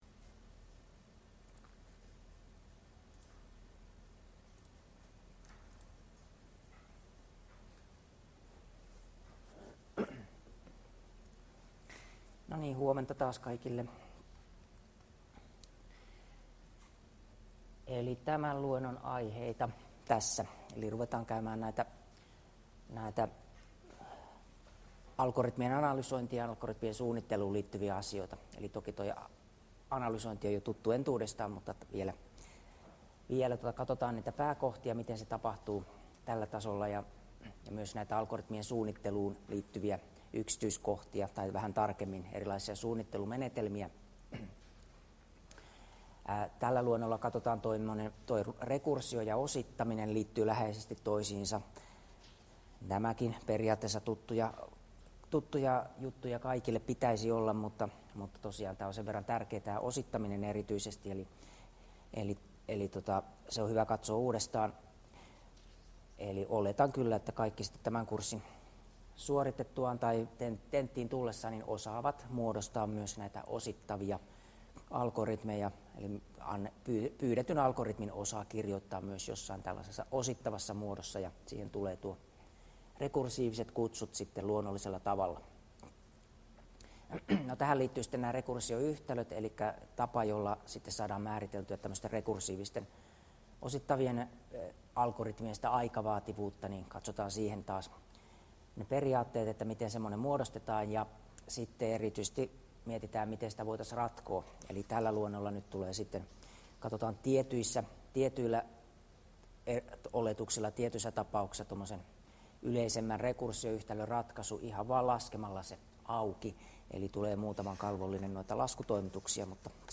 Luento 8 — Moniviestin